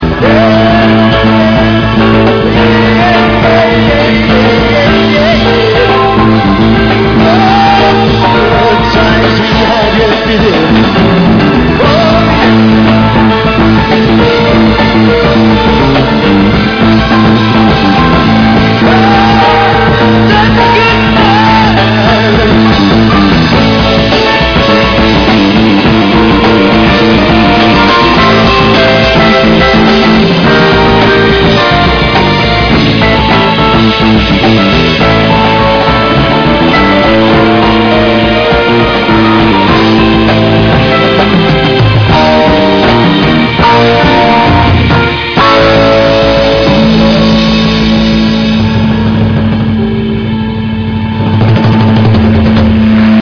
vocals, bass, and piano
guitar
Hammond organ and synthesisers
drums.